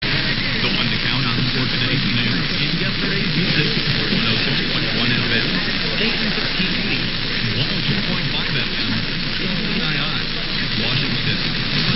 Twin Cities AM DX log